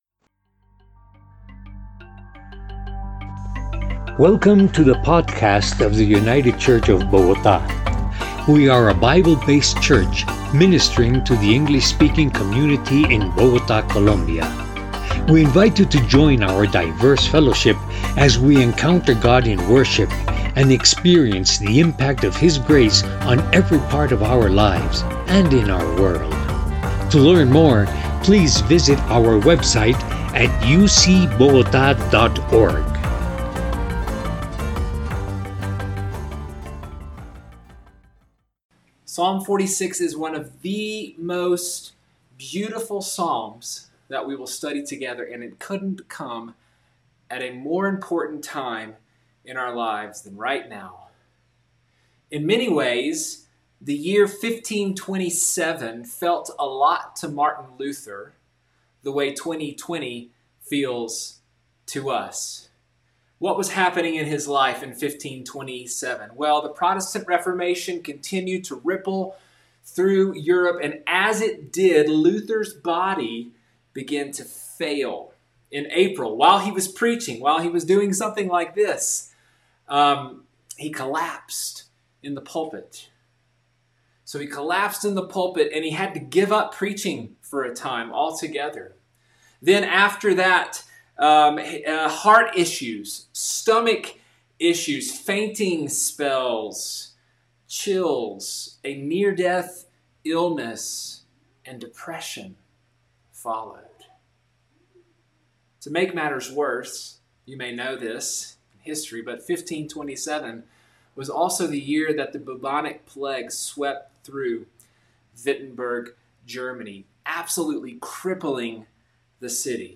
By United Church of Bogotá | 2020-07-12T16:35:49-05:00 July 12th, 2020 | Categories: Sermons | Tags: Summer Psalms | Comments Off on 1527 All Over Again Share This Story, Choose Your Platform!